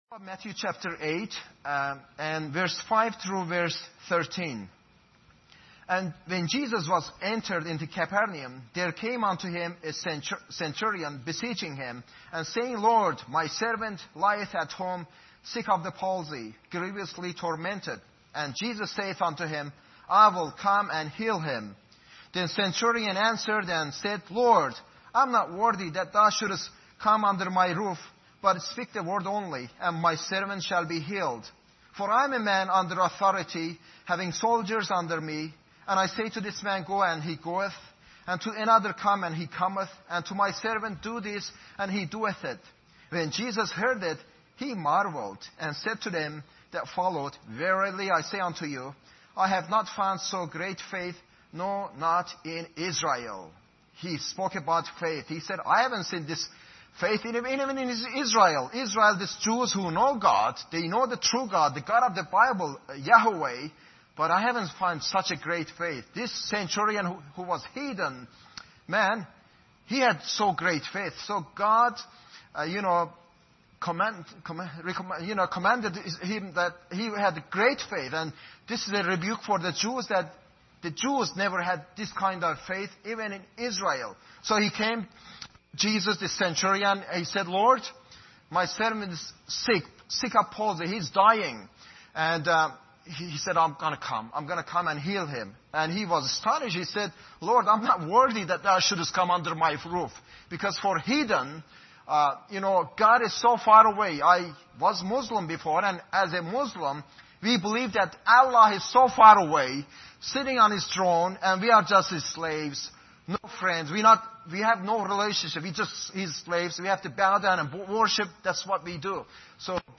Matthew 8:5-13 Service Type: Sunday Evening Bible Text